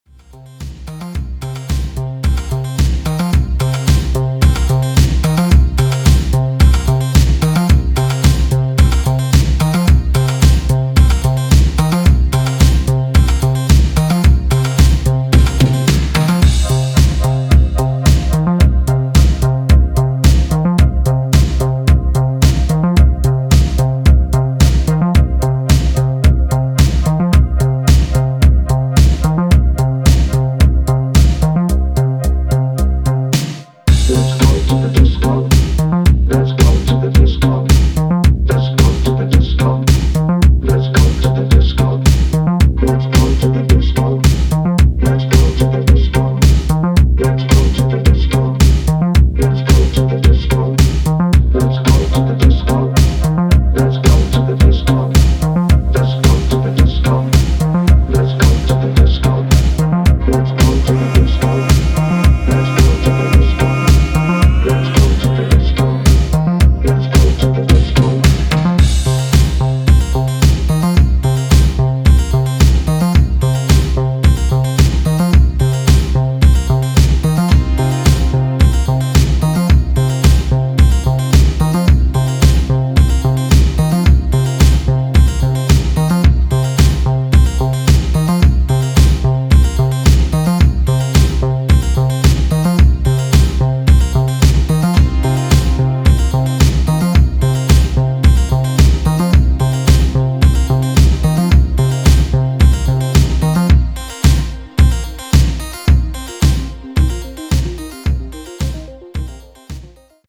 full pelt Dancefloor 4-way